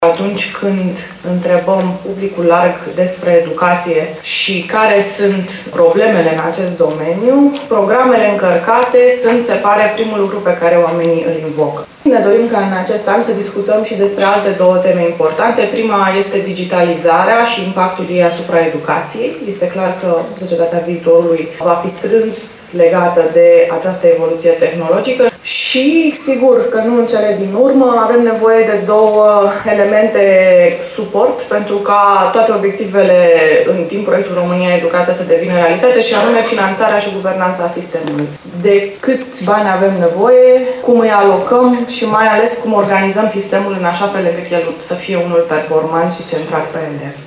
Departamentul de Educație și Cercetare din cadrul Administrației Prezidențiale a organizat astăzi, la teatrul din Suceava, dezbaterea națională Arhitectura curriculară pentru învățământul primar, gimnazial și liceal.
Dezbaterea a început cu sesiunea privind rezultatele proiectului „România Educată”, prezentate de consilierul de stat LIGIA DECA din cadrul Departamentului de Educație și Cercetare al Administrației Prezindențiale.